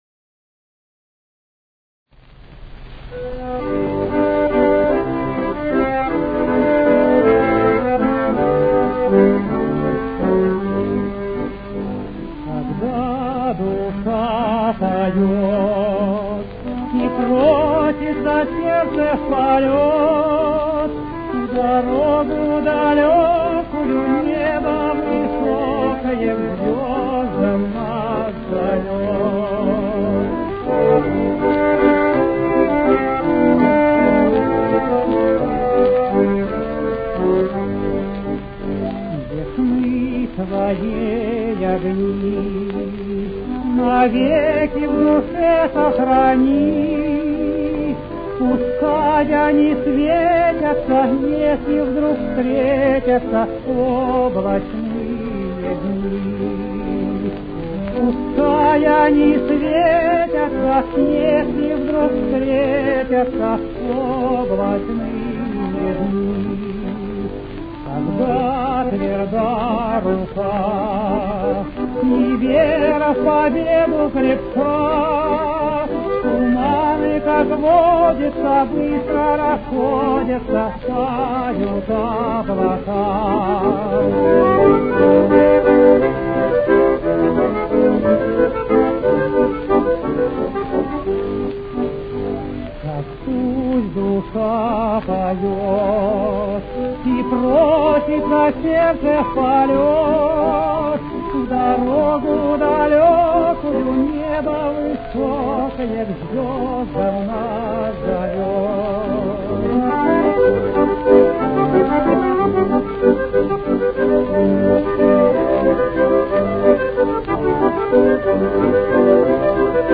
с очень низким качеством (16 – 32 кБит/с)
Темп: 162.